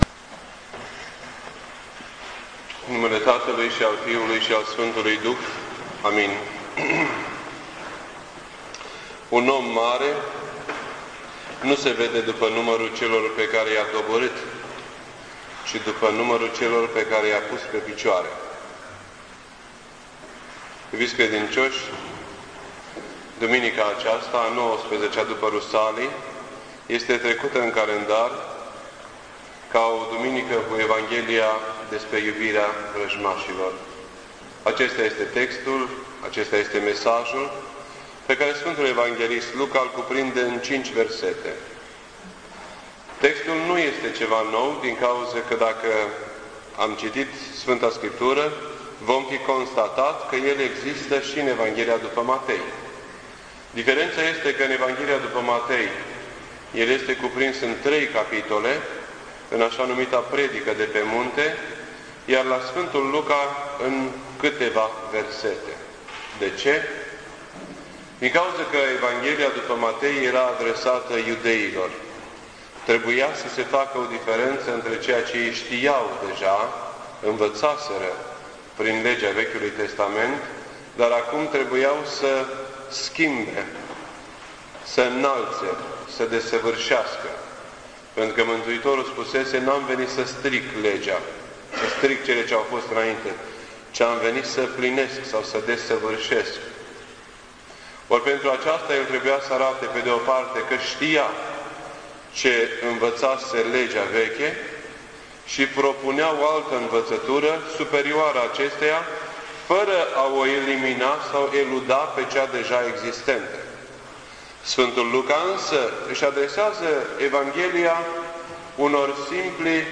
This entry was posted on Sunday, September 30th, 2007 at 10:06 AM and is filed under Predici ortodoxe in format audio.